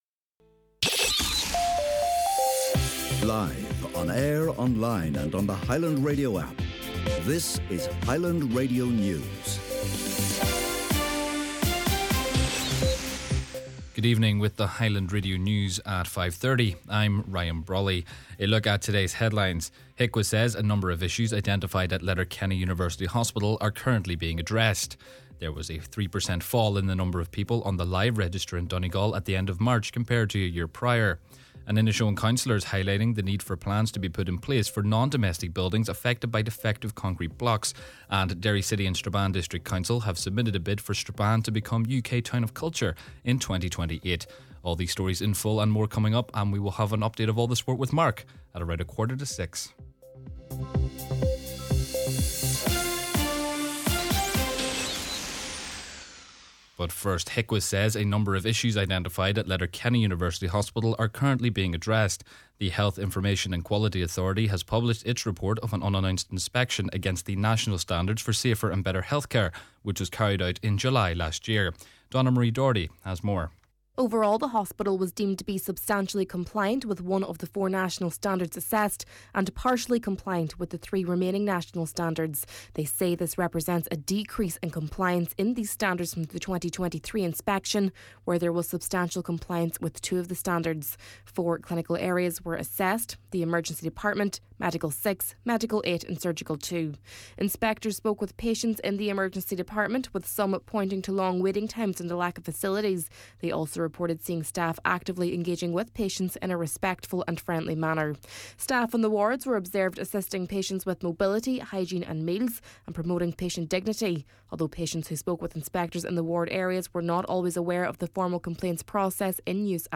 Main Evening News, Sport, Obituary Notices and Farming News – Thursday, April 2nd